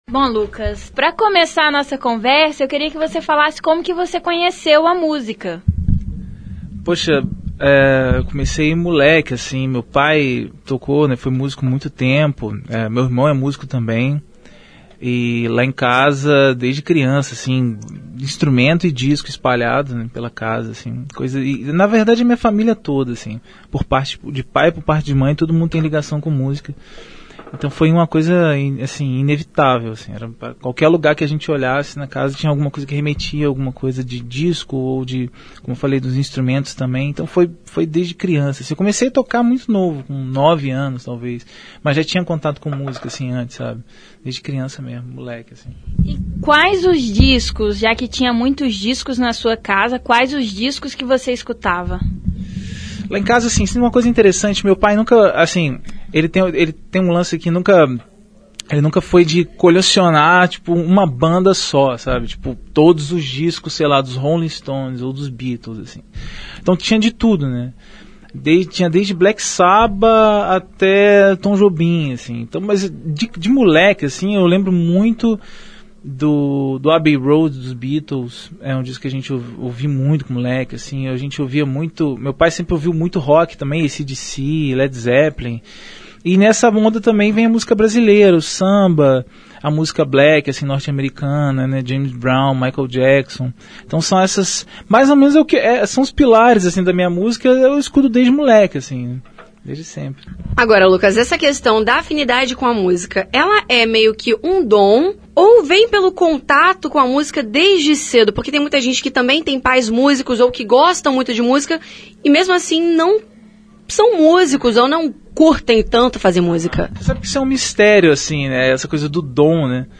Confira a entrevista completa com o cantor!